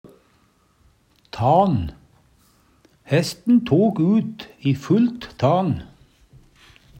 DIALEKTORD PÅ NORMERT NORSK tan vilt sprang, tan Eintal ubunde Eintal bunde Fleirtal ubunde Fleirtal bunde tan tane Eksempel på bruk Hesten tok ut i fullt tan.